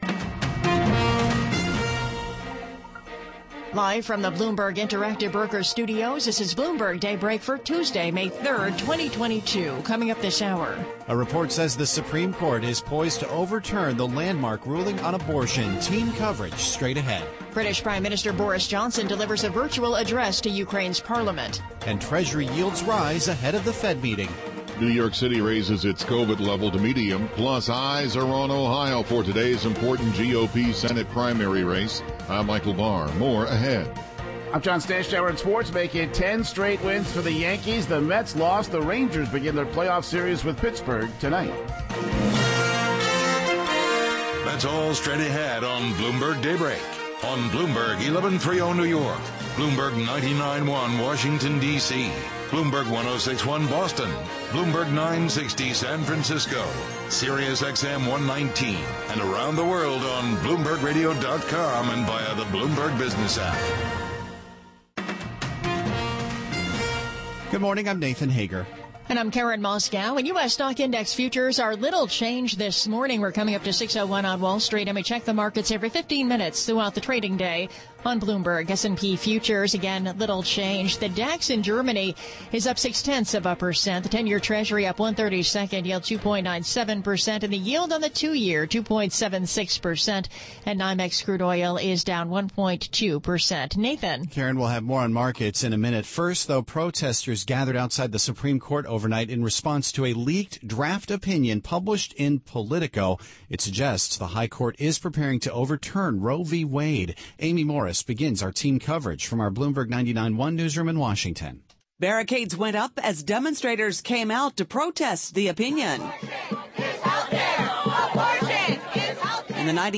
Bloomberg Daybreak: May 3, 2022 - Hour 2 (Radio) 42:50 Share